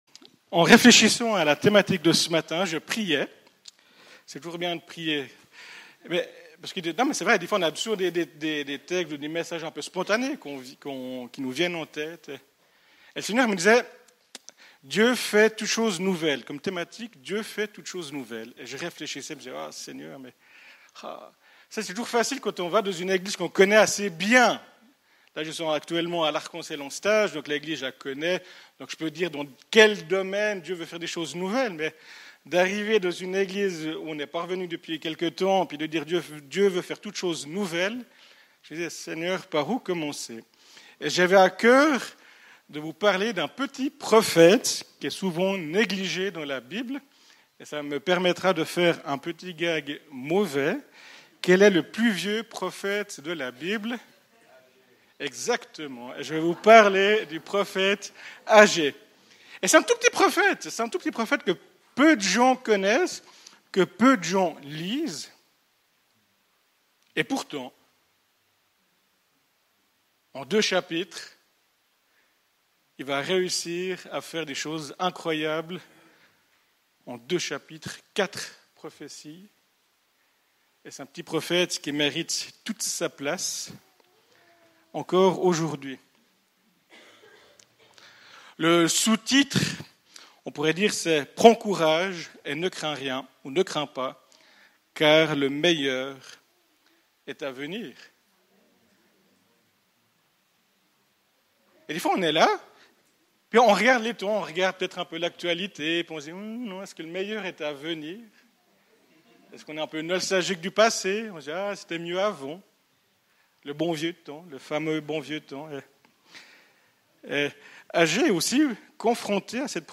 Sur cette page vous avez la possibilité d'écouter ou de télécharger certains messages apportés durant les cultes, ainsi que d'autres enseignements comme les cours Enracinés.